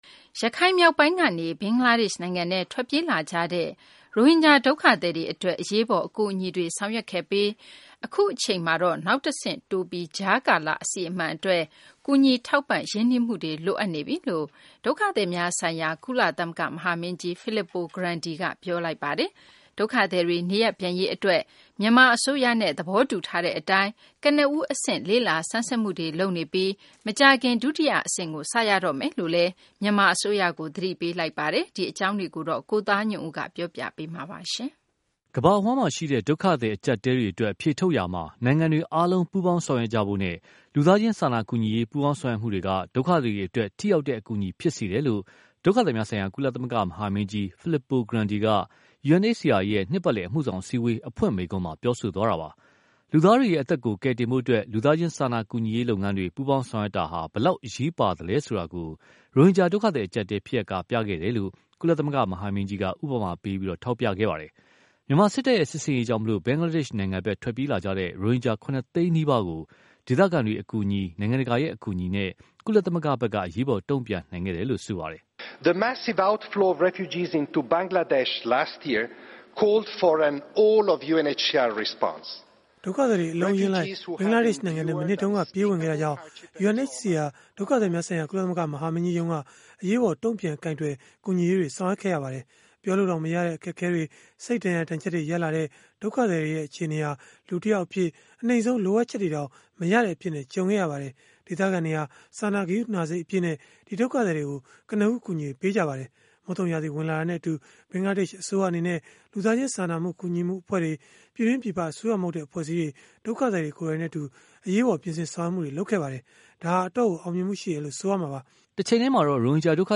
ကမ္ဘာအဝှမ်းမှာရှိတဲ့ ဒုက္ခသည်အကြပ်အတည်းတွေအတွက် အဖြေထုတ်ရာမှာ နိုင်ငံတွေအားလုံးပူးပေါင်းဆောင်ရွက်ကြဖို့နဲ့၊ လူသားချင်းစာနာကူညီရေး ပူးပေါင်းဆောင်ရွက်မှုတွေက ဒုက္ခသည်တွေအတွက် ထိရောက်တဲ့ အကူအညီဖြစ်စေတယ်လို့ ဒုက္ခသည်များဆိုင်ရာ ကုလသမဂ္ဂမဟာမင်းကြီး Filippo Grandi က UNHCR ရဲ့ နှစ်ပတ်လည် အမှုဆောင်အစည်းအဝေး အဖွင့်မိန့်ခွန်းမှာ ပြောဆိုသွားတာပါ။ လူသားတွေရဲ့ အသက်ကို ကယ်တင်ဖို့ လူသားချင်းစာနာကူညီရေးလုပ်ငန်းတွေ ပူးပေါင်းဆောင်ရွက်မှုဟာ ဘယ်လောက်အရေးပါလဲဆိုတာကို ရိုဟင်ဂျာဒုက္ခသည်အကြပ်အတည်းဖြစ်ရပ်က ပြခဲ့တယ်လို့လည်း ကုလ မဟာမင်းကြီးက ဥပမာပေး ထောက်ပြသွားပါတယ်။ မြန်မာစစ်တပ်ရဲ့ စစ်ဆင်ရေးကြောင့် ဘင်္ဂလားဒေရ်ှနိုင်ငံဖက် ထွက်ပြေးလာကြတဲ့ ရိုဟင်ဂျာ ၇ သိန်းနီးပါးကို ဒေသခံတွေအကူအညီ နိုင်ငံတကာရဲ့အကူအညီနဲ့ ကုလသမဂ္ဂဟာ အရေးပေါ် တုံ့ပြန်နိုင်ခဲ့တယ်လို့ ပြောပါတယ်။